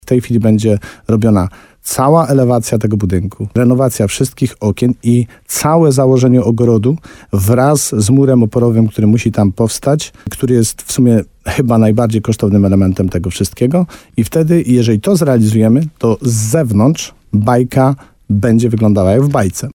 Wójt gminy Ochotnica Dolna Tadeusz Królczyk podkreśla, że właśnie rozpoczyna się trzeci – najważniejszy etap.